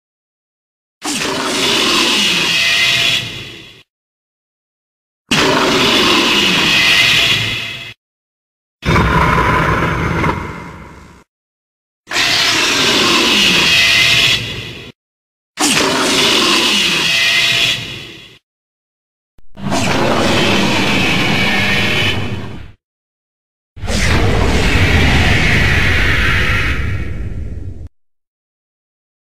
Golza_Roars.ogg